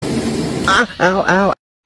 Goofy Ahh Weird Noises Meme Grunts